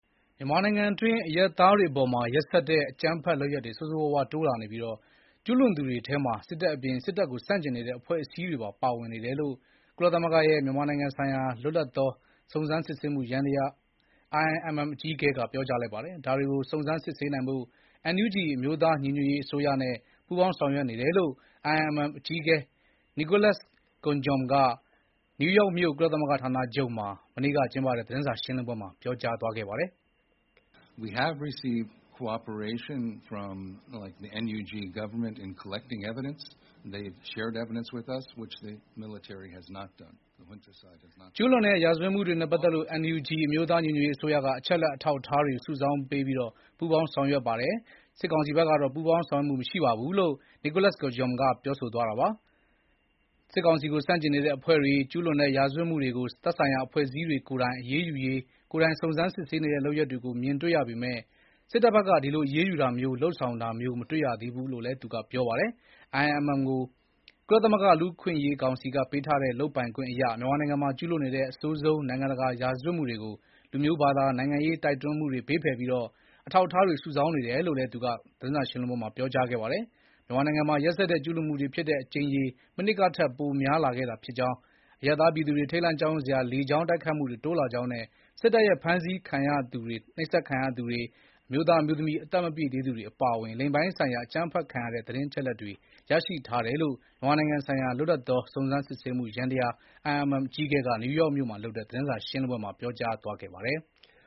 ရက်စက်တဲ့ အကြမ်းဖက်မှုတွေအကြောင်း IIMM အကြီးအကဲ သတင်းစာရှင်း